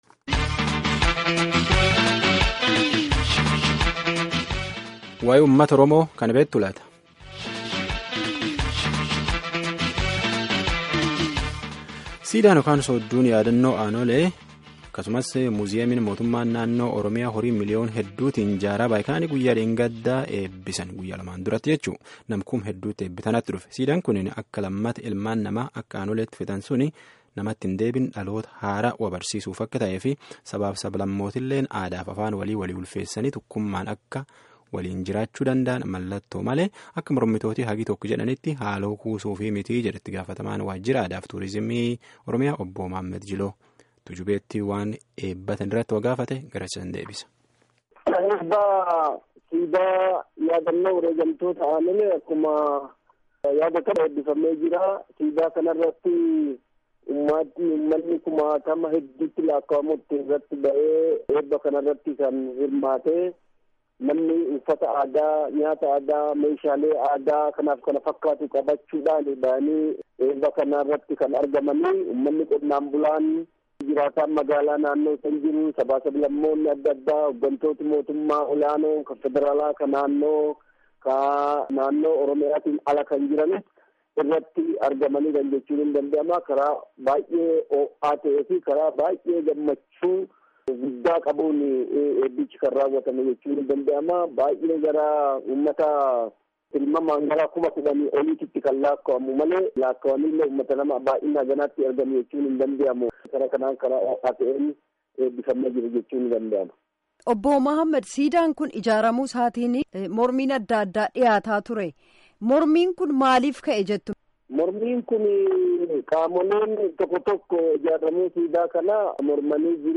Gaaffii fi deebii gaggeeffanne armaa gadiitti caqasaa.